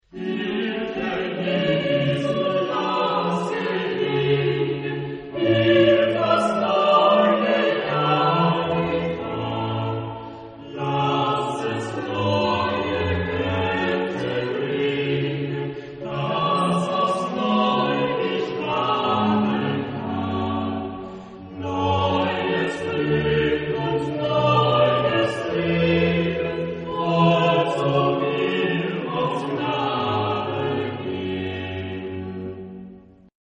Genre-Style-Form: Sacred ; Baroque ; Chorale
Type of Choir: SATB  (4 mixed voices )
Instrumentation: Instrumental ensemble  (2 instrumental part(s))
Instruments: Basso continuo